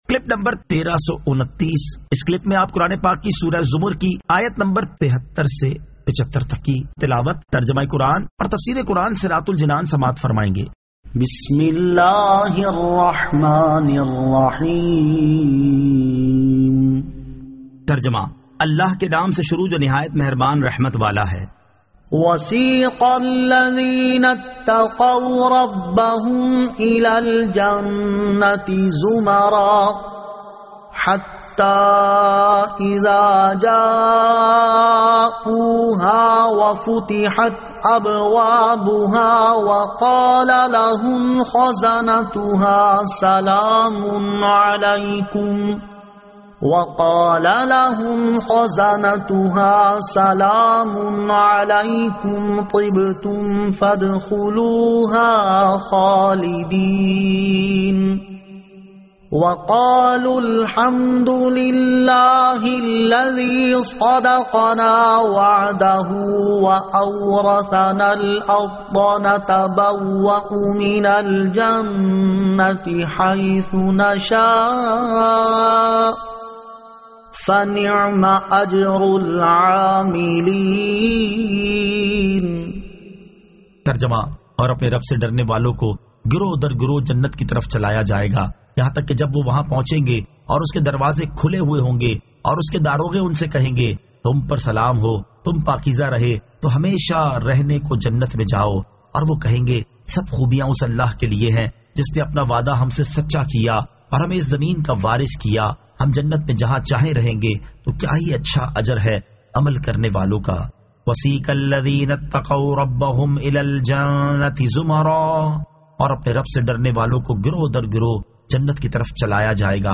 Surah Az-Zamar 73 To 75 Tilawat , Tarjama , Tafseer